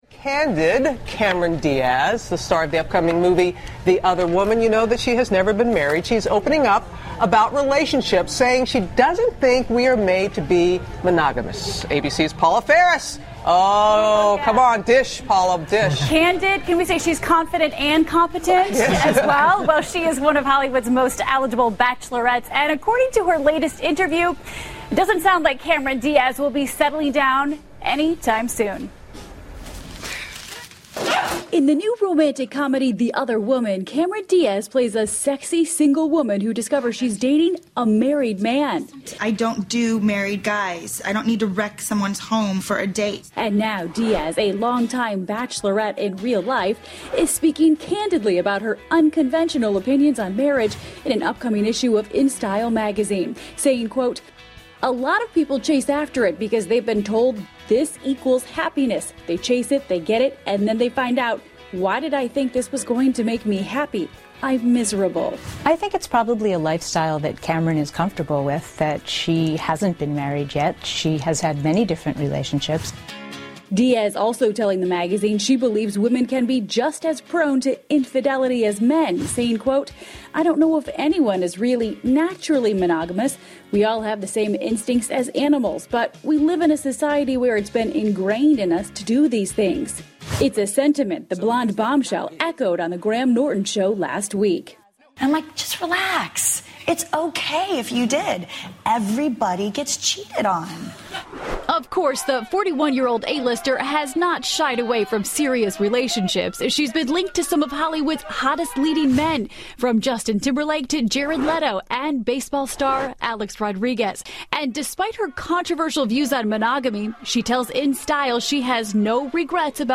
访谈录 2014-04-25&04-27 卡梅隆·迪亚兹谈一夫一妻制 听力文件下载—在线英语听力室